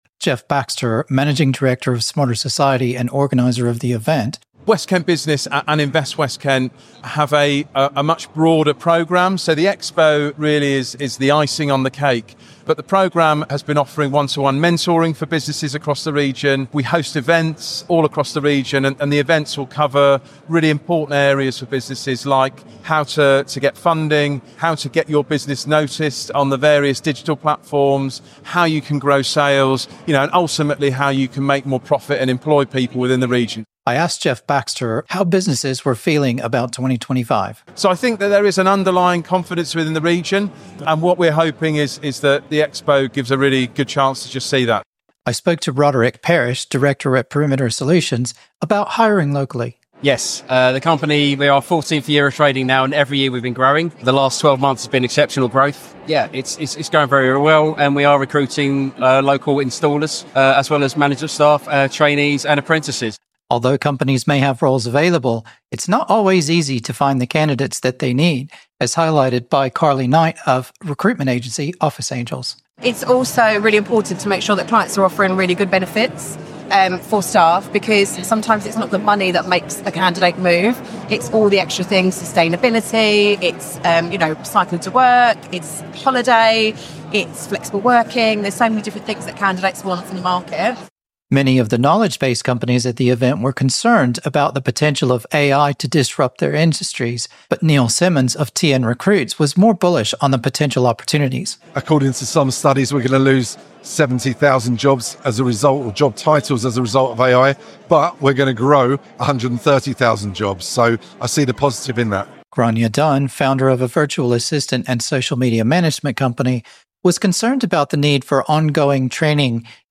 The event is held every year and in 2025 there were 50 exhibitors and around 500 delegates.